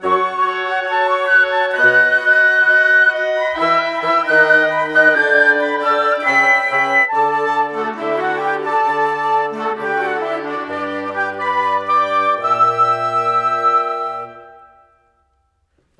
Rock-Pop 01 Winds 01.wav